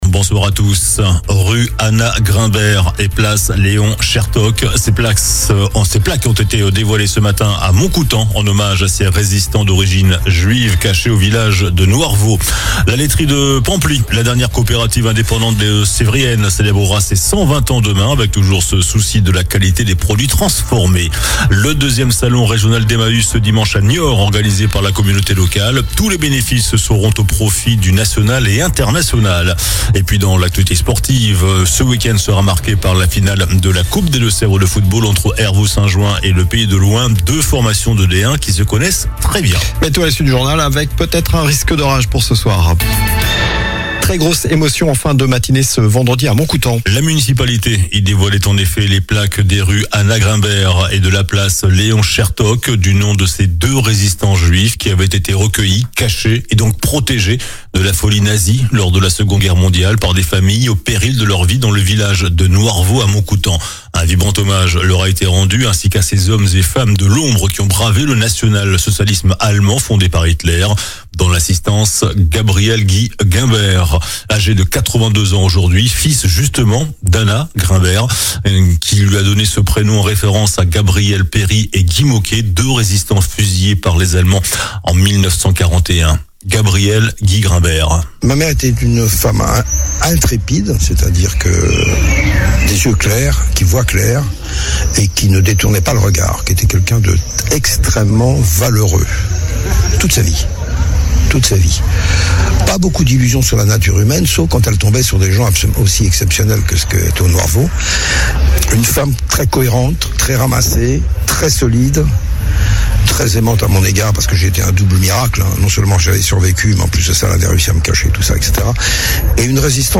JOURNAL DU VENDREDI 13 JUIN ( SOIR )